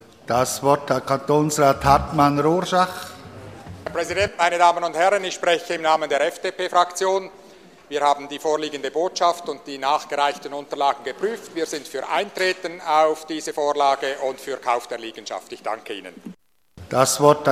Session des Kantonsrates vom 2. bis 4. Juni 2014